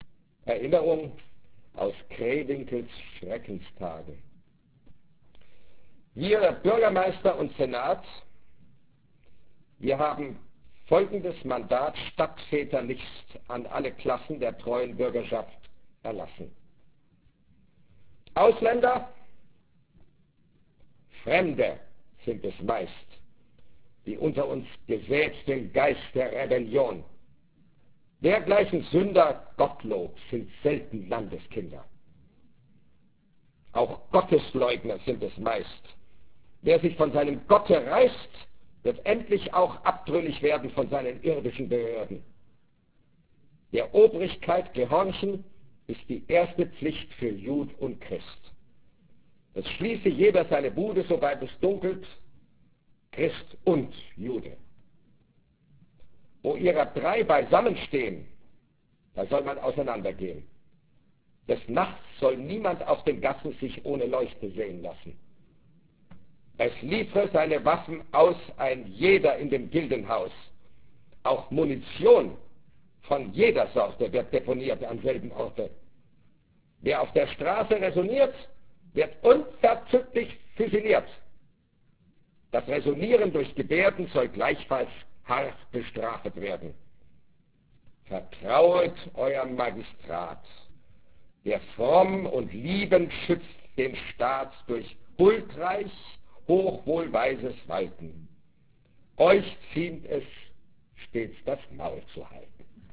Heinrich Heine Jahr Düsseldorf 1997 - Karlheinz Böhm liest Heinrich Heine - Aufzeichnungen vom 1. Dezember 1996 im Heinrich-Heine-Institut Düsseldorf